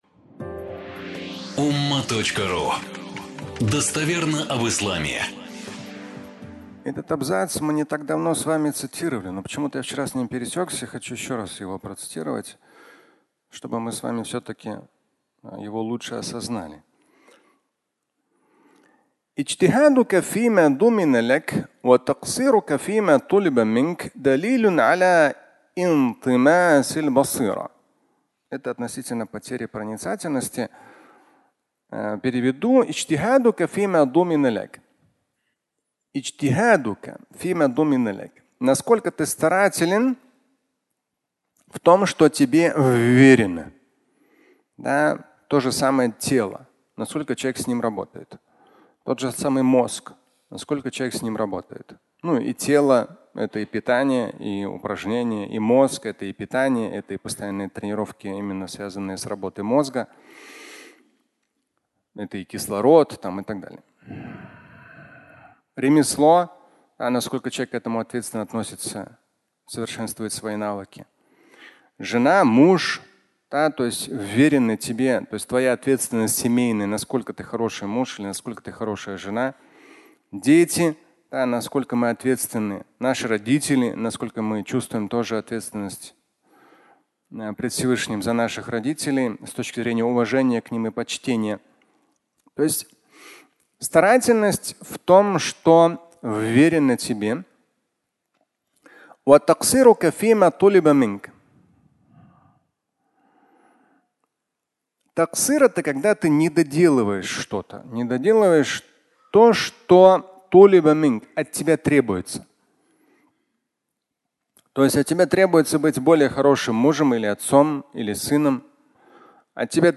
Потеря проницательности (аудиолекция)